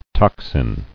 [tox·in]